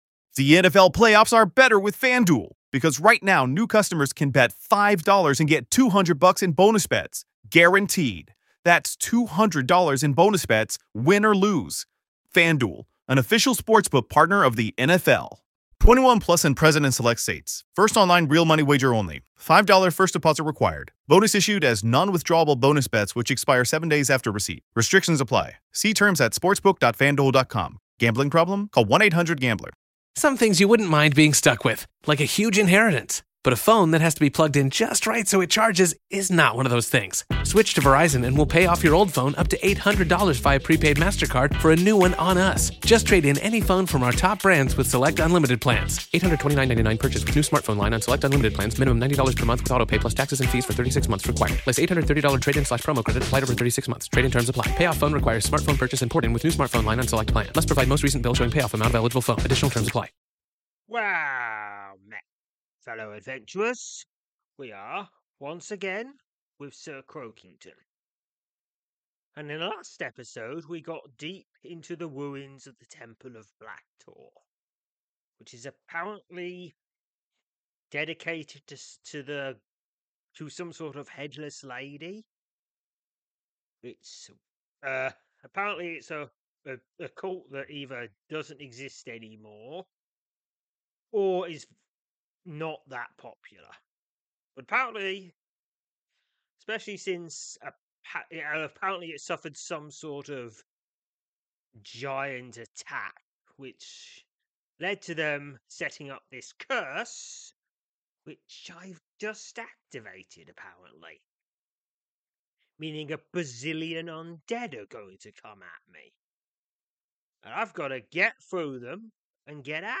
I read out and play a selection of adventures from the the fantasy browser RPG Sryth.